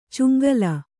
♪ cuŋgala